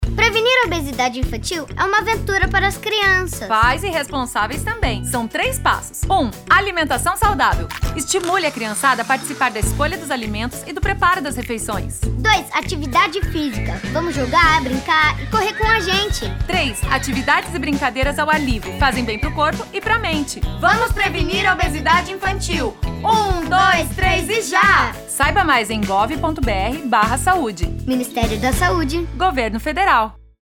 Spot - Campanha da Prevenção da Obesidade Infantil.mp3 — Ministério da Saúde